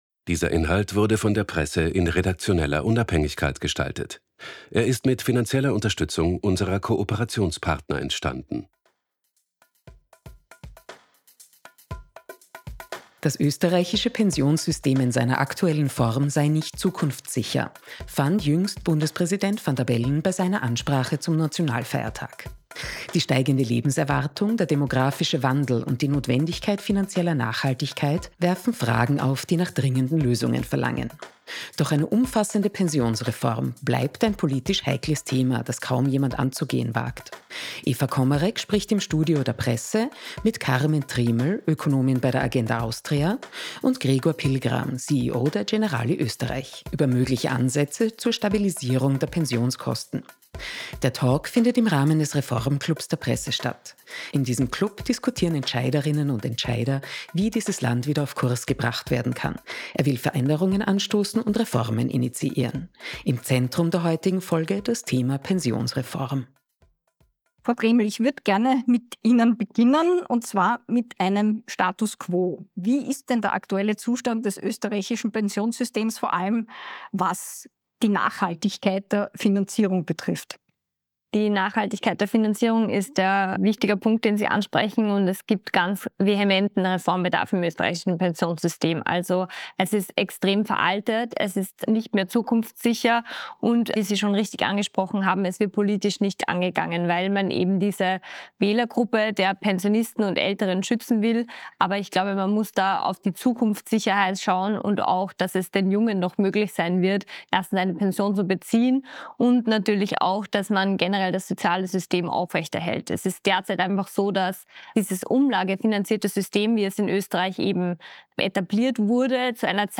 Mixdown_-_Reformagenda_-_Diskussion_2_-_V2.mp3